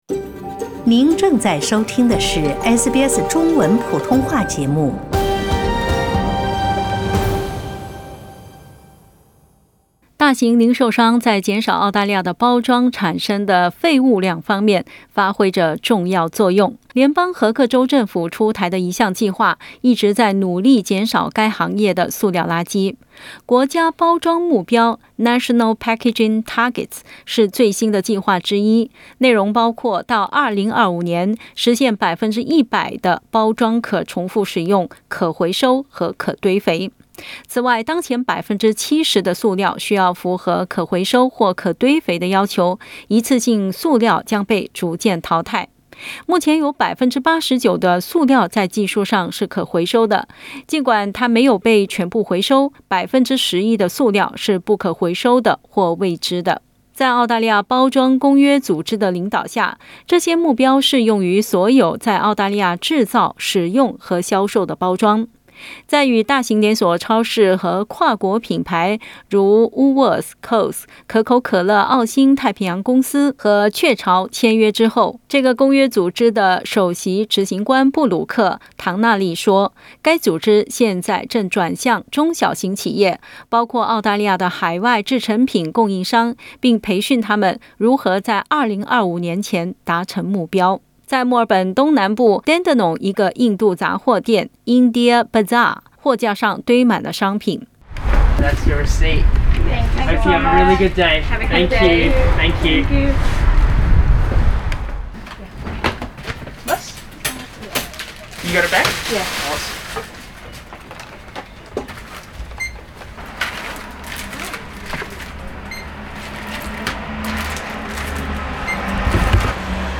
在澳大利亞，絕大多數從海外進口的亞洲食品都寀取小包裝的形式，這令獨立雜貨店的經營者面臨塑料垃圾的問題。（點擊圖片收聽報道）